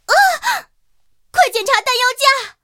野蜂中破语音.OGG